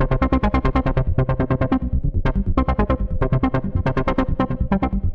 Index of /musicradar/dystopian-drone-samples/Droney Arps/140bpm
DD_DroneyArp1_140-C.wav